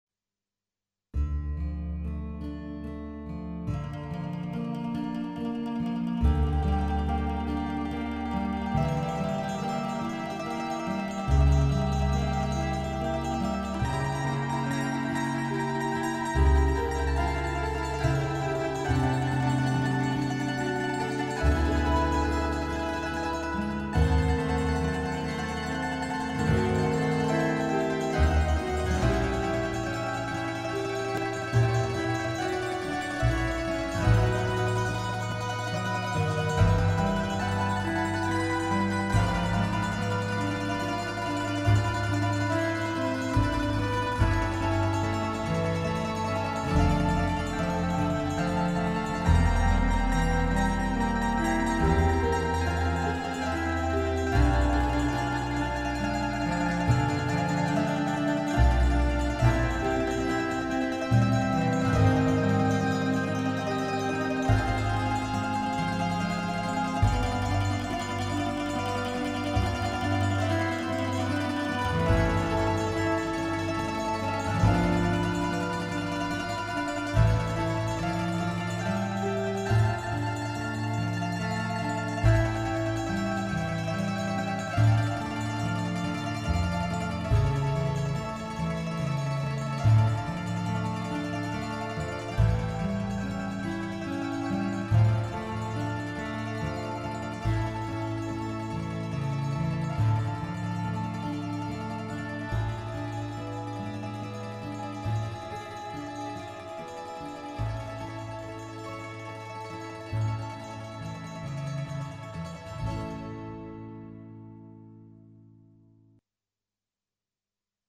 orchestra a plettro